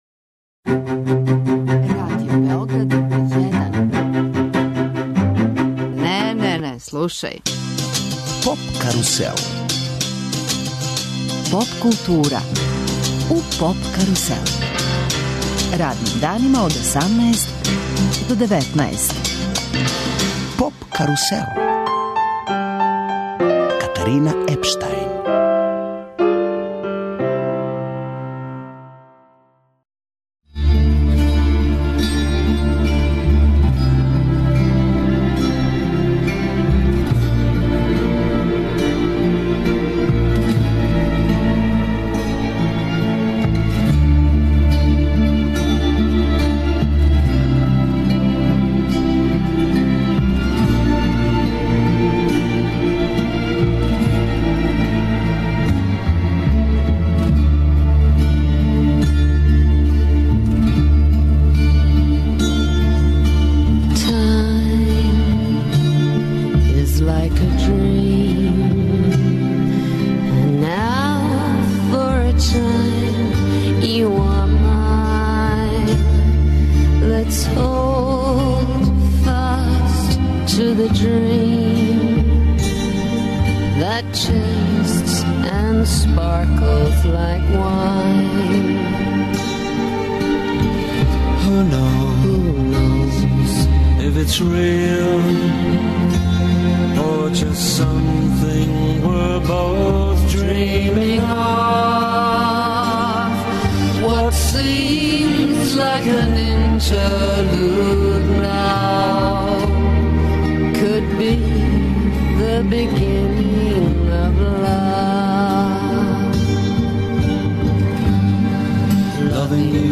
Представљамо Шкртице, мали ад хок непретенциозан бенд, мешавина психоделије, хард рока, блуза и експерименталне музике.